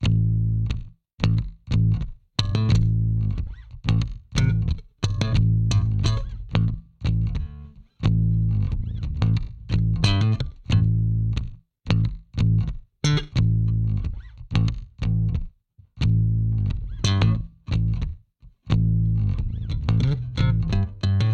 仅限志道节拍大鼓
标签： 90 bpm Hip Hop Loops Bass Loops 3.59 MB wav Key : Unknown
声道立体声